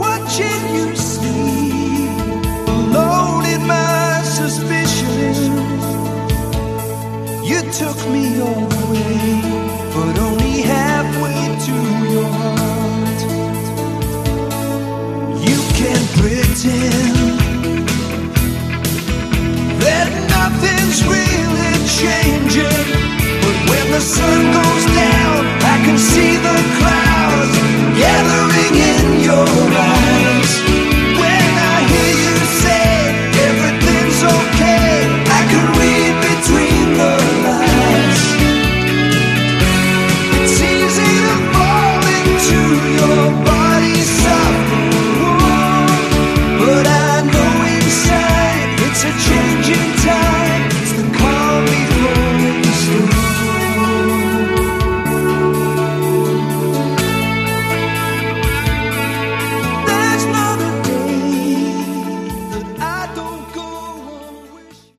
Category: Lite/West Coast AOR